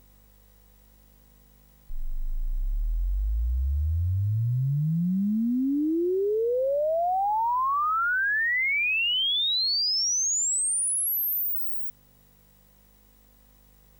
sid-measurements - SID chip measurements